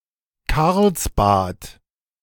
Karlsbad (German: [ˈkaʁlsˌbaːt]
De-Karlsbad.ogg.mp3